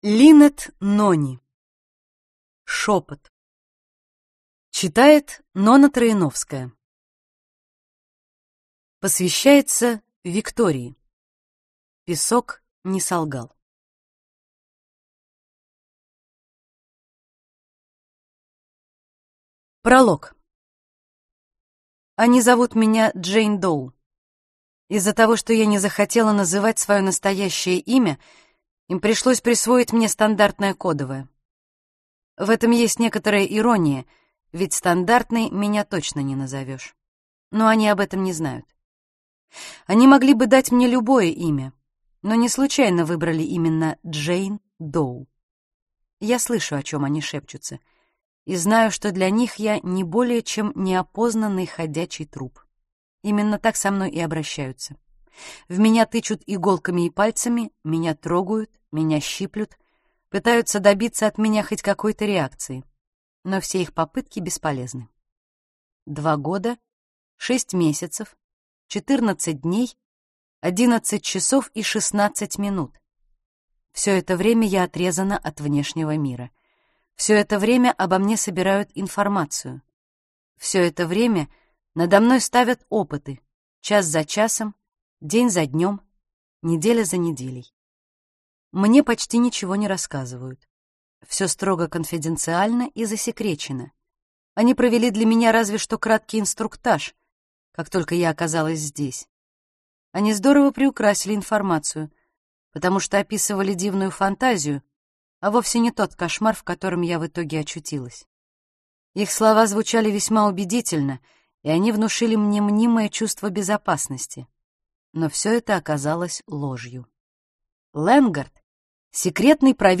Аудиокнига Шепот | Библиотека аудиокниг